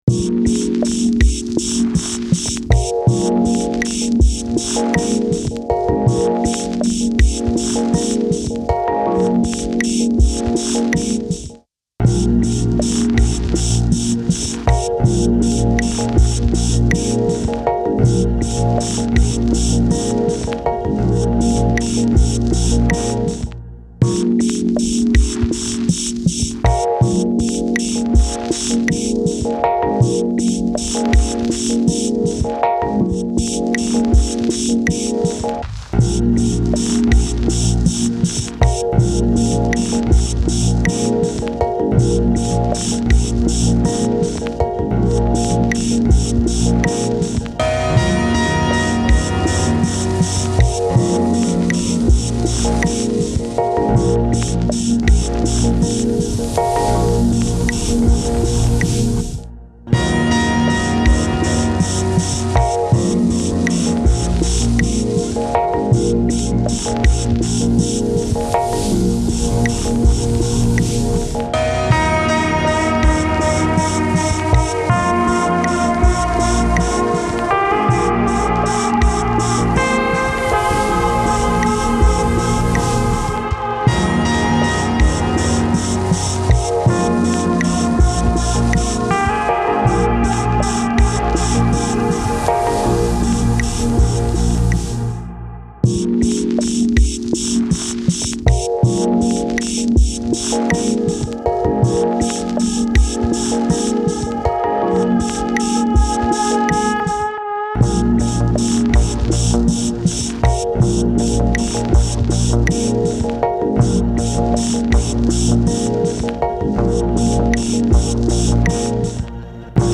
er lieferte loops und groben arrangement, ich spielte gitarre dazu.
wie man hören kann, waren wir zwei keine verfechter von standards. hoffentlich gefällts jemanden, aber vorsicht: es kann auch langweilig klingen.